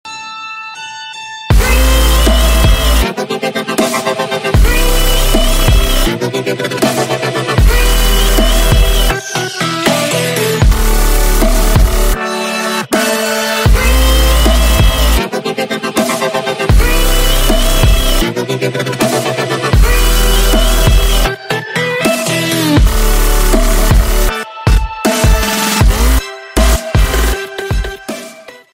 Громкие Рингтоны С Басами » # Рингтоны Без Слов
Рингтоны Ремиксы » # Рингтоны Электроника